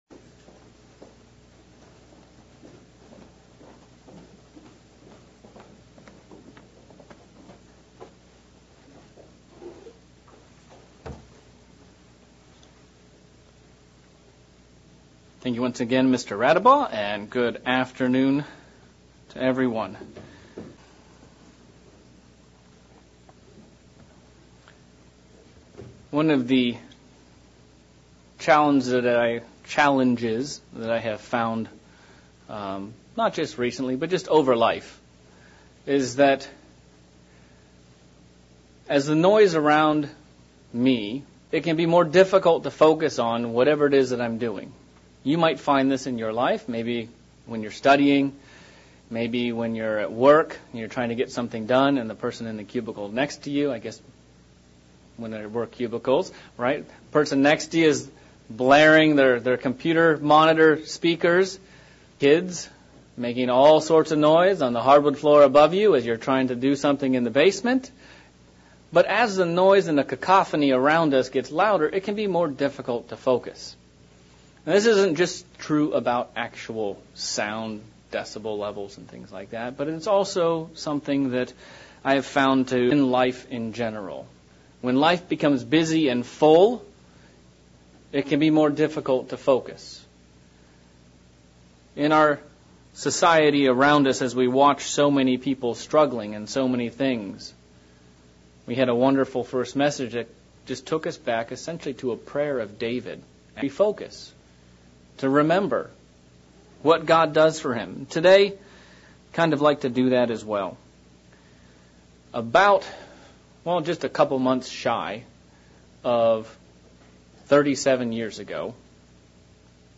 Sermon looking back at the old concept of the Trrunk of the Tree. What things would make up the "trunk of the tree" for a Christian?